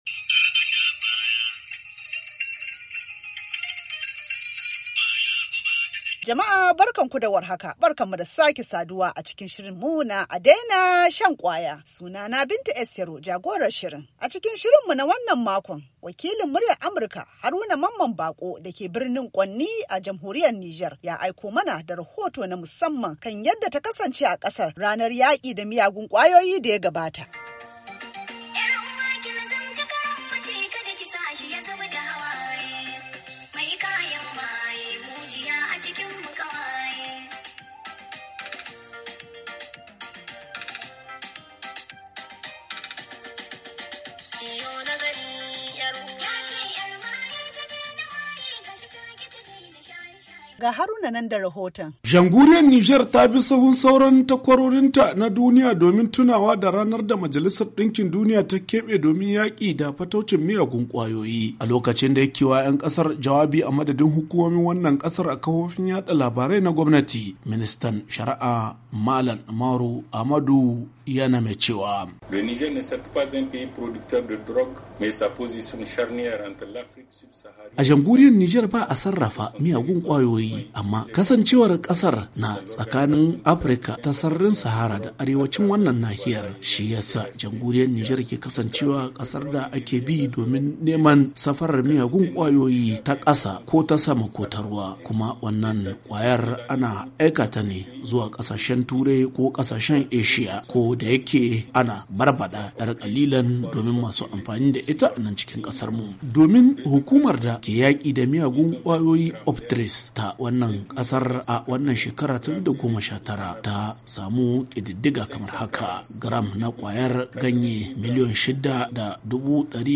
A Daina Shan Kwaya: Rahoto Na Musamman Akan Yadda Ta Kasance A Jamhuriyar Nijar Ranar Yaki Da Miyagun Kwayoyi Da Ta Gabata